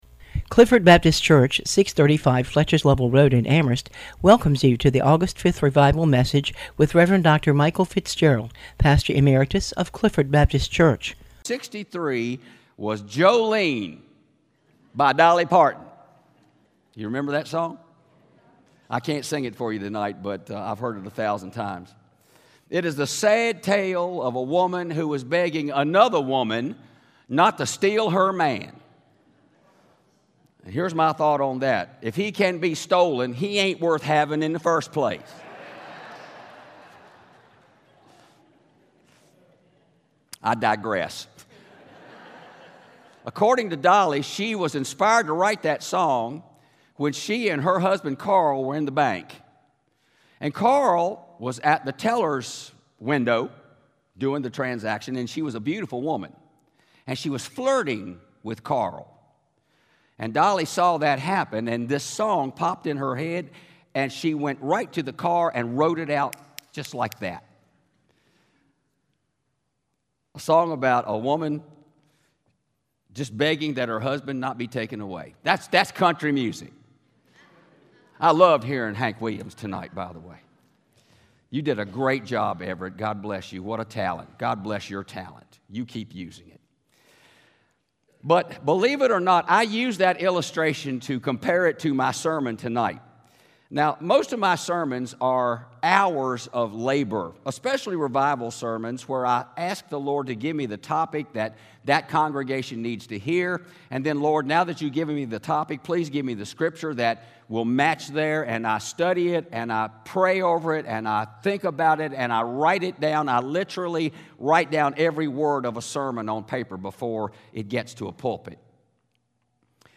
Clifford Baptist Revival Service